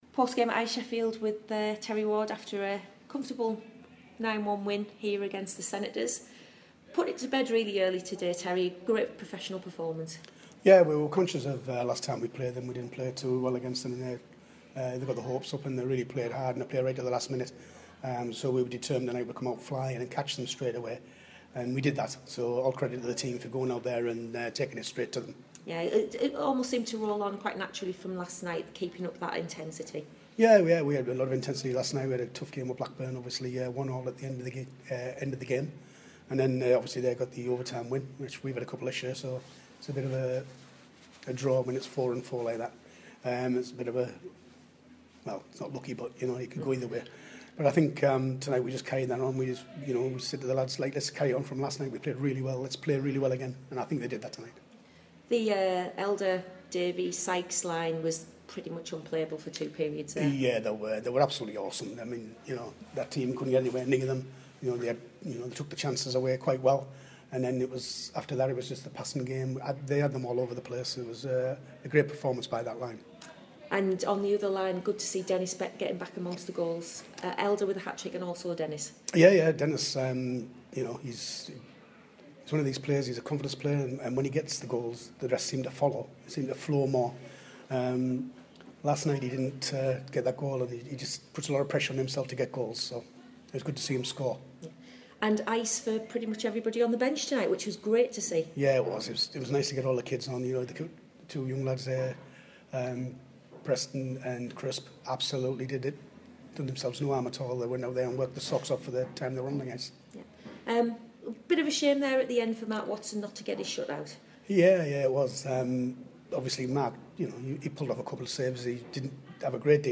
post game in Sheffield on Sunday afternoon after Stars 9-1 success over Senators.